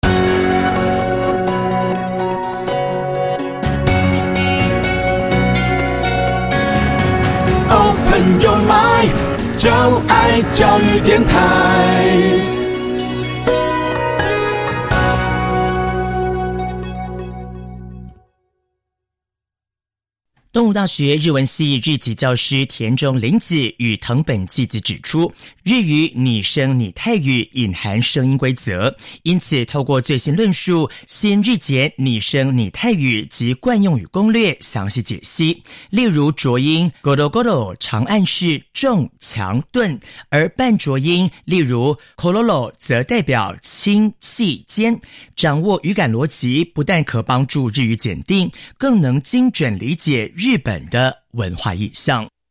• 全書錄音：由專業日籍老師錄製的音檔，利用寂天雲APP隨時隨地輕鬆收聽，讓學習變得輕鬆自如。